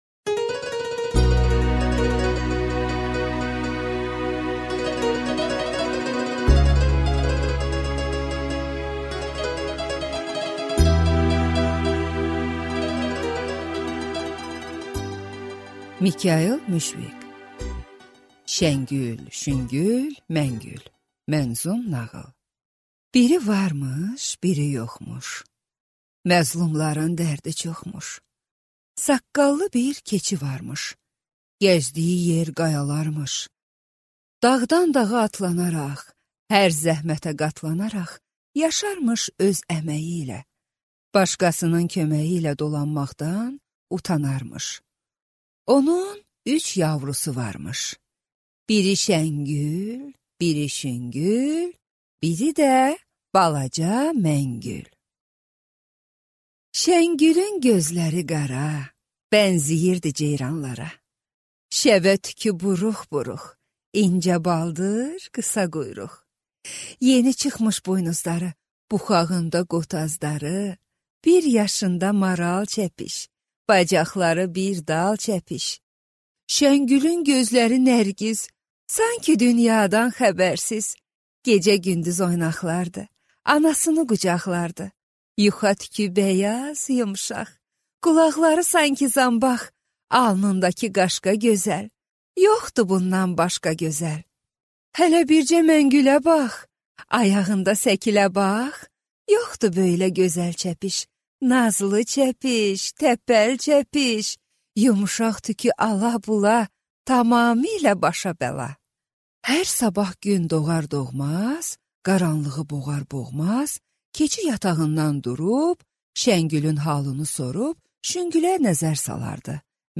Аудиокнига Şəngül, Şüngül, Məngül | Библиотека аудиокниг